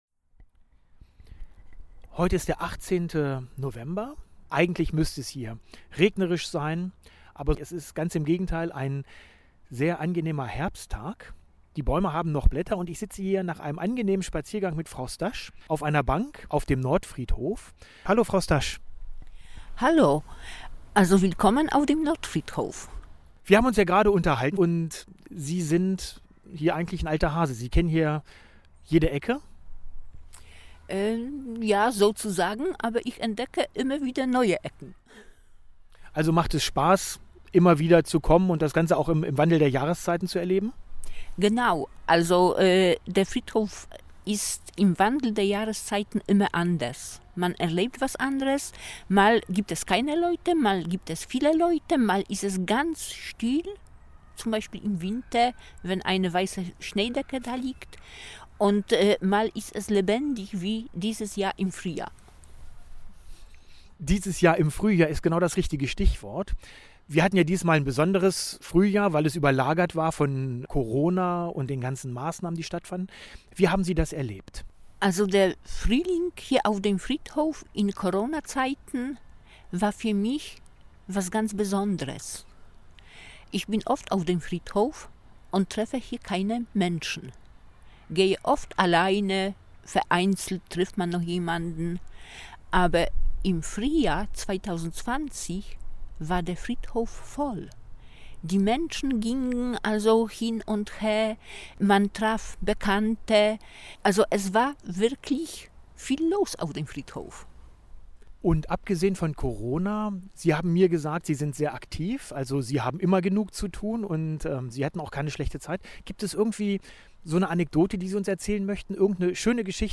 Sie hat uns mitgenommen an ihren Lieblingsort, wo sie jeden Stein kennt und auch zu jedem eine Geschichte weiß. Heute erzählt sie uns von der Poesie, die auch in Kleinigkeiten liegen kann.